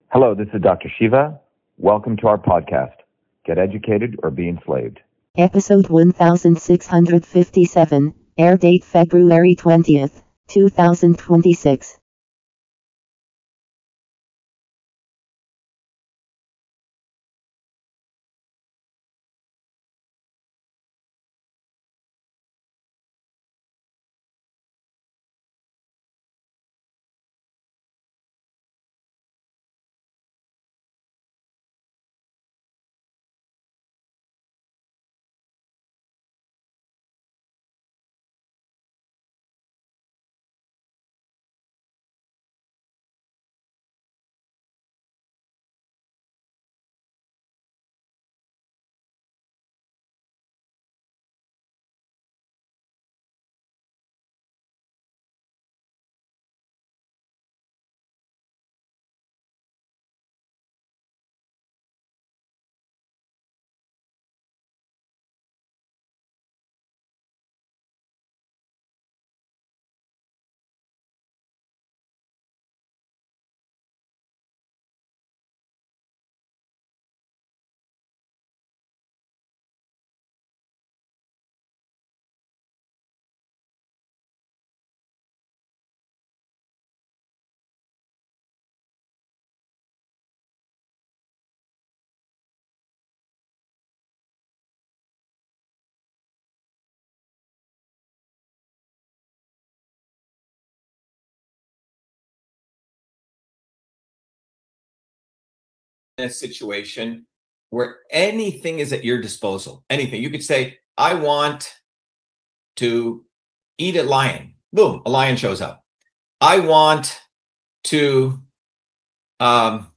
Beyond Epstein E 2026-02-21 Likes 1 Download Share In this interview